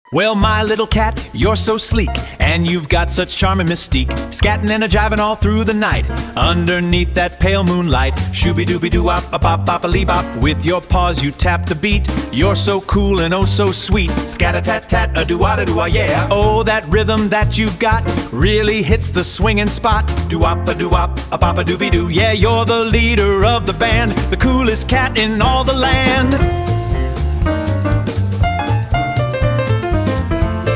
生成した音楽のサンプルがこちら。
サンプルミュージック② (男性ボーカル)